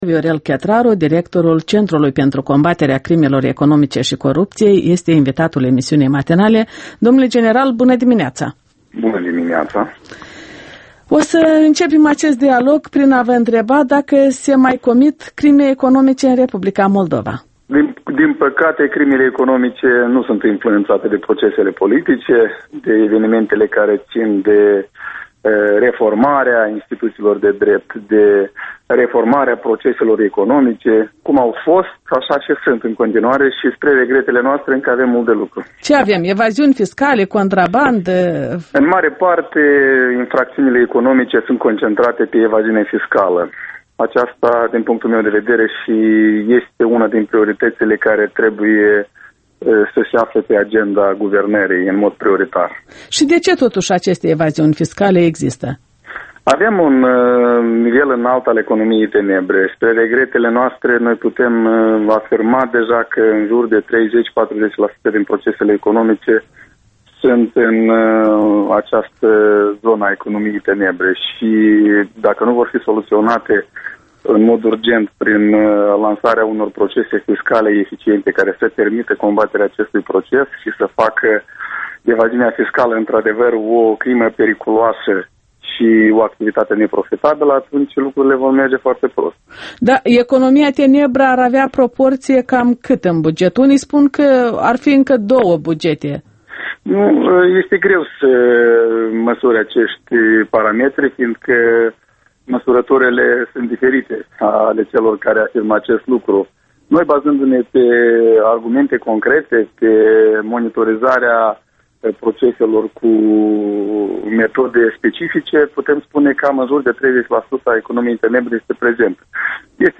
Interviul dimineții la Europa Liberă: cu Viorel Chetraru despre urgența combaterii evaziunii fiscale